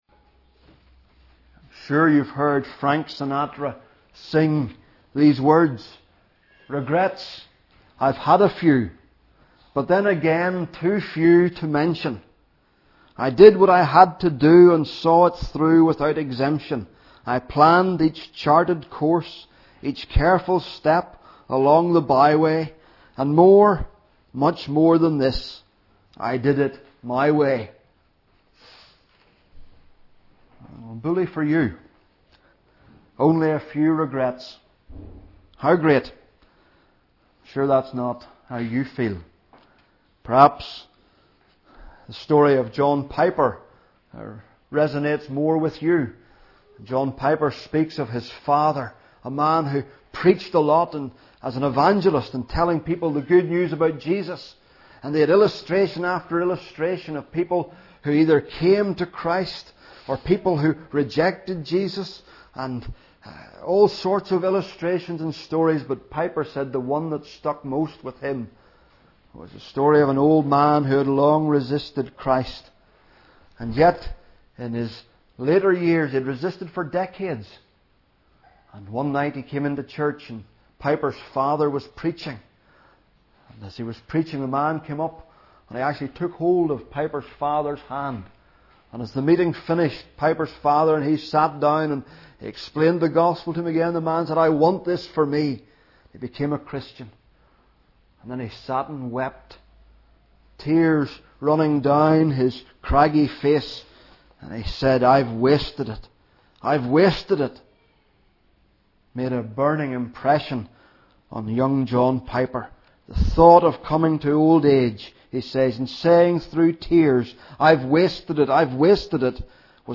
Hear the latest sermons preached in NLF, or browse the back catalogue to find something to feed your soul.